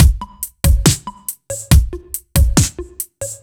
Index of /musicradar/french-house-chillout-samples/140bpm/Beats
FHC_BeatD_140-01.wav